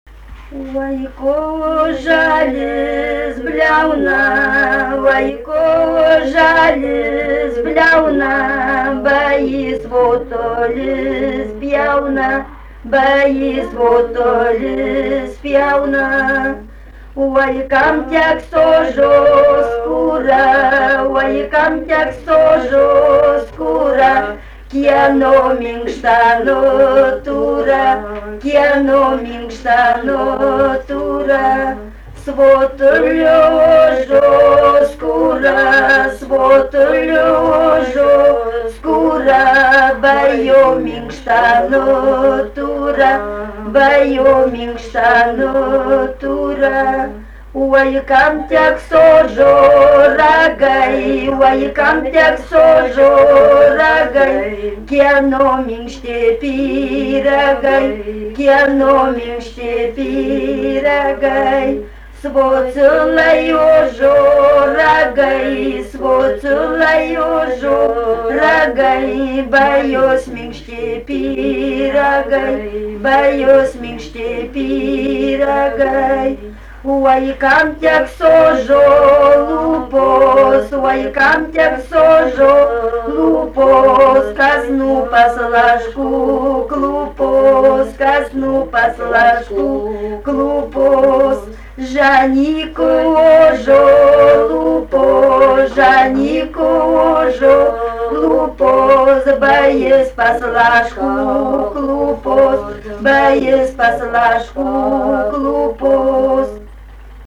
daina, vestuvių
Pauosupė
vokalinis
2 balsai